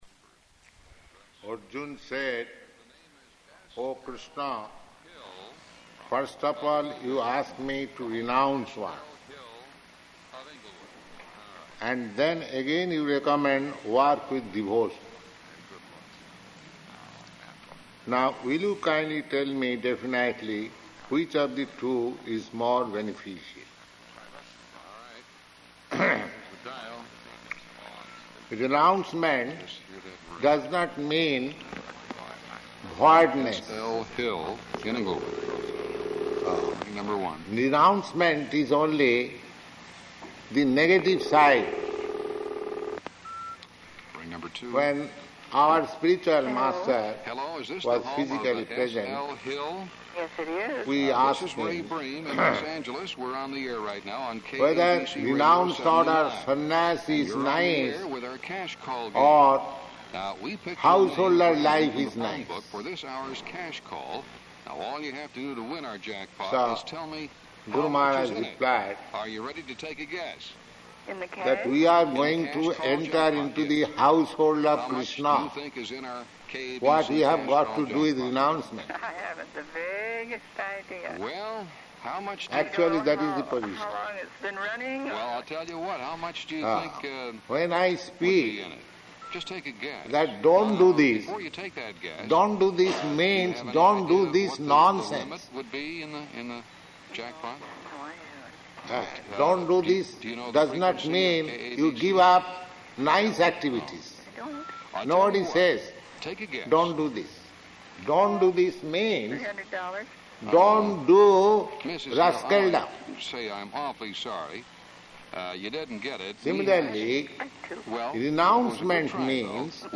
Bhagavad-gītā 5.1–2 [radio interference]
Type: Bhagavad-gita
Location: Los Angeles